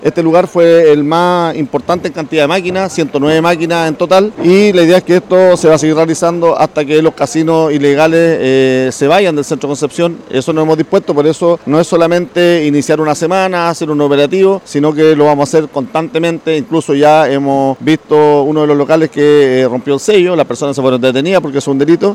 Un nuevo operativo del Municipio en conjunto con Carabineros terminó con la incautación de 109 máquinas, una de las incautaciones más grandes hasta el momento. Así lo relató el alcalde Héctor Muñoz, en medio del operativo.